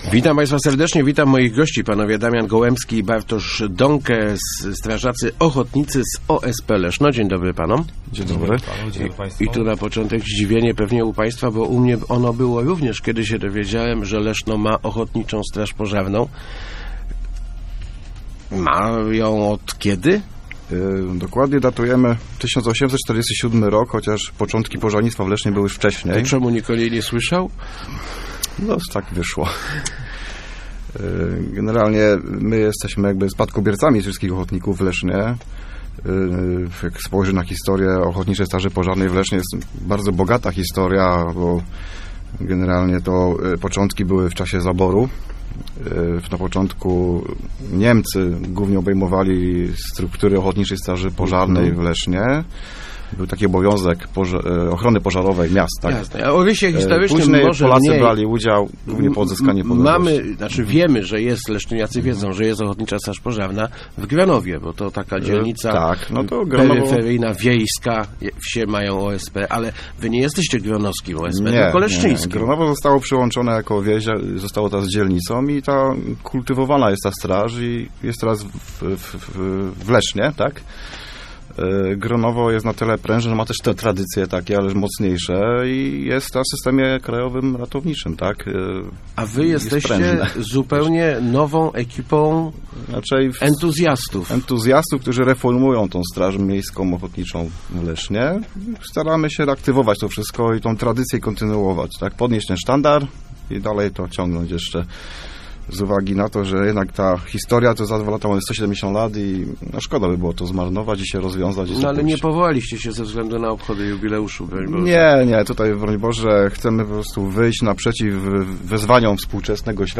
członkowie OSP Leszno.